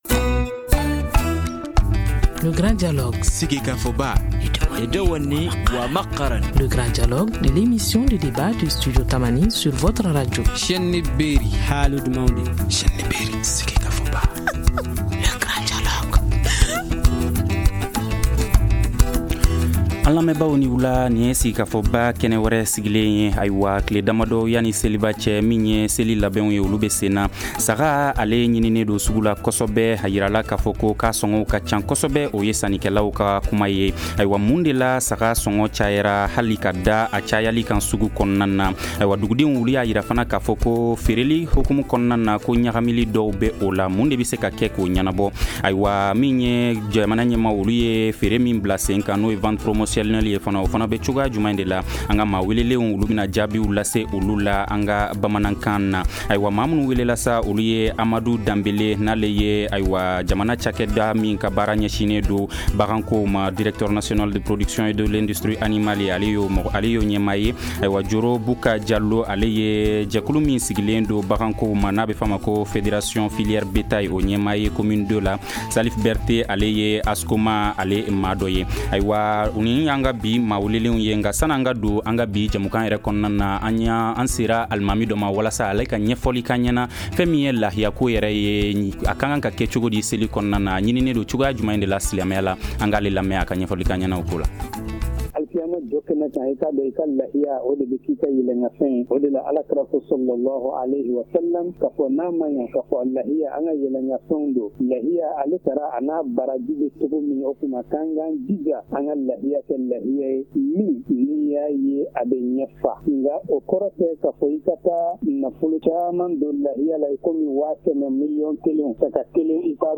Nos invités apporteront des réponses à ces questions et bien d’autres en langue Bambara.